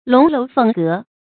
龍樓鳳閣 注音： ㄌㄨㄙˊ ㄌㄡˊ ㄈㄥˋ ㄍㄜˊ 讀音讀法： 意思解釋： ①帝王的宮殿、樓閣。